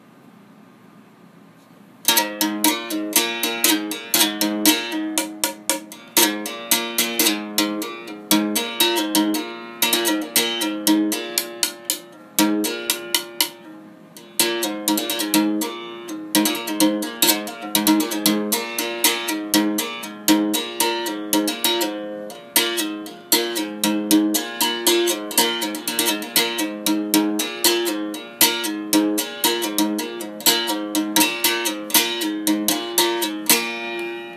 As a means to develop dexterity and control, I have always enjoyed the idea of playing Wilcoxon’s rudimental etudes on the berimbau.
As a first musical offering for 2015, here is a single pass (slowly!) through Wilcoxon no. 27.